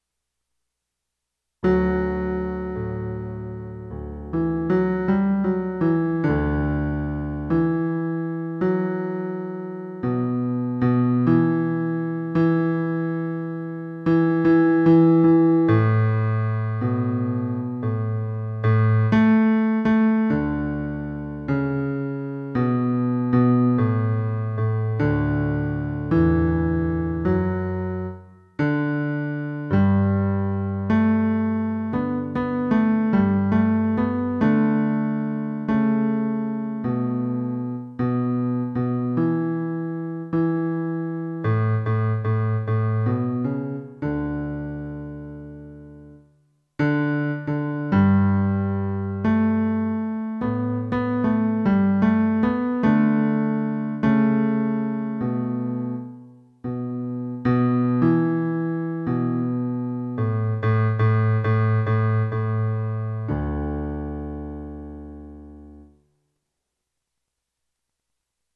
Lord, You Have Come - Bass